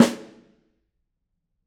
Snare2-HitSN_v9_rr1_Sum.wav